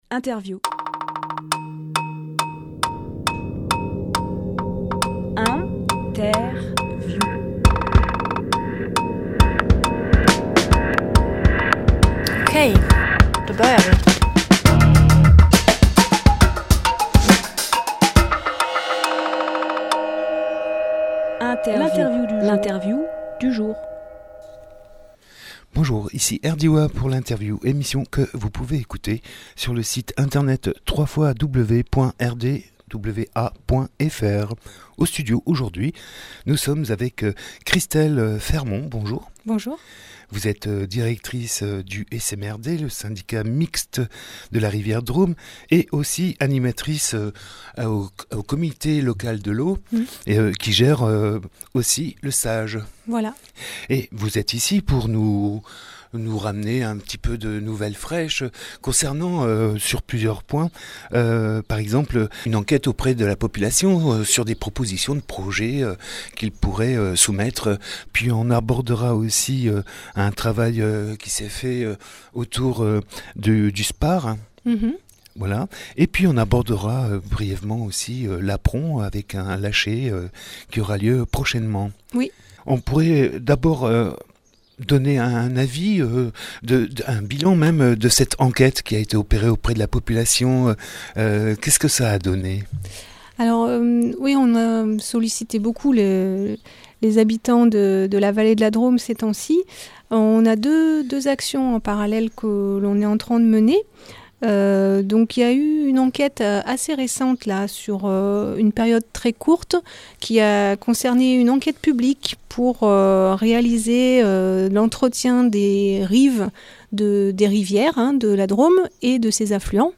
Emission - Interview SMRD : retour d’enquête auprès de la population Publié le 30 mai 2018 Partager sur…
Lieu : Studio RDWA